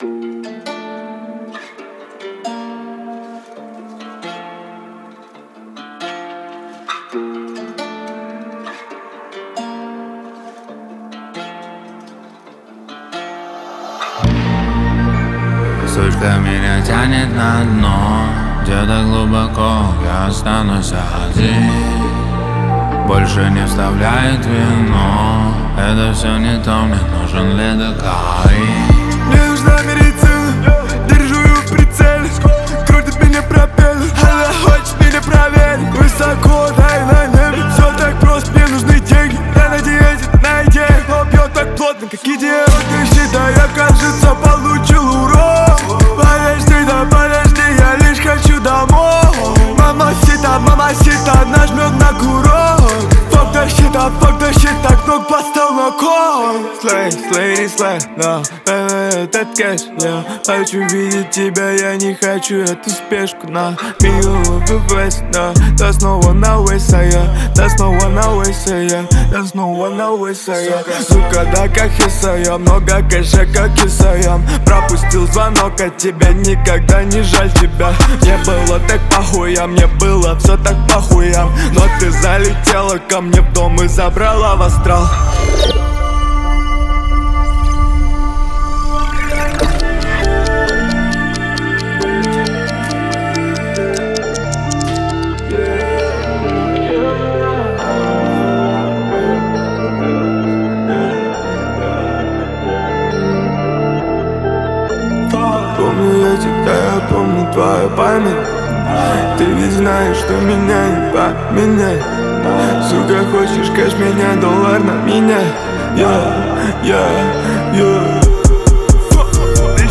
Трек размещён в разделе Русские песни / Рок.